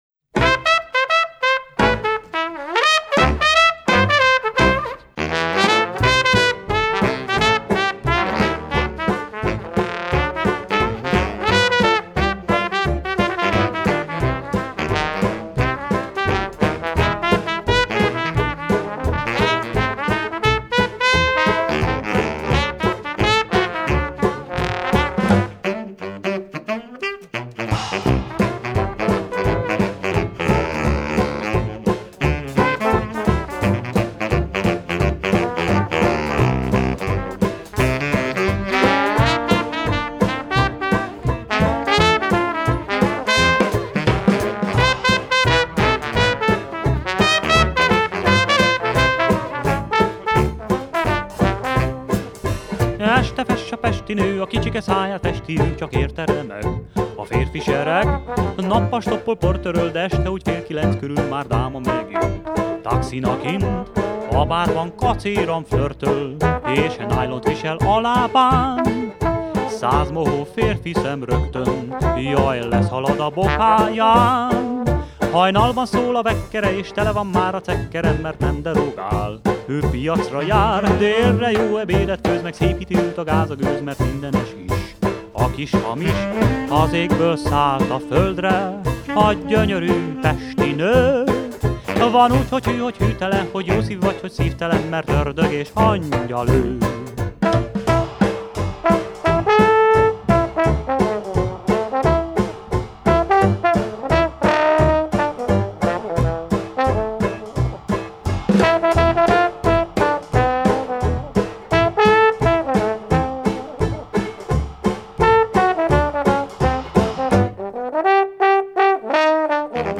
Джаз